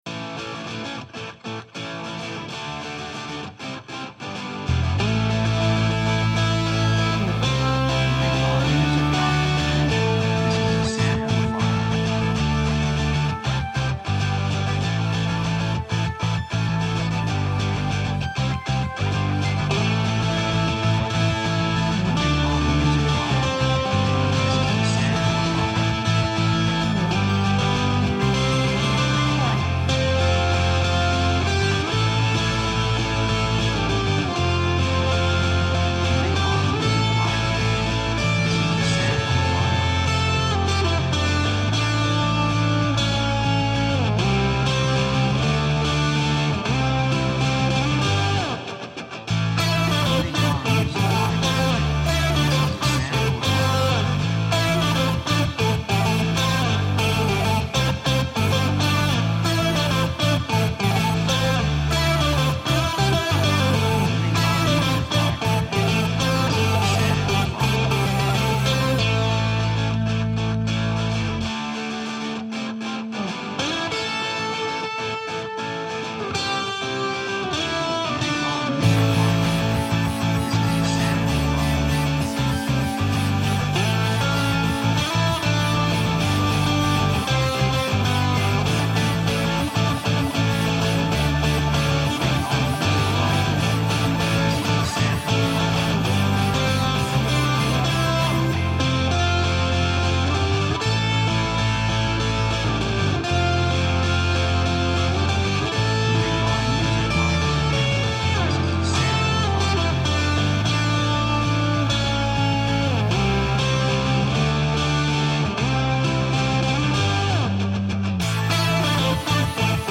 雰囲気エネルギッシュ, 壮大, 幸せ, 高揚感, 喜び
曲調ポジティブ
楽器エレキギター, 手拍子
サブジャンルポップロック, インディーロック
テンポとても速い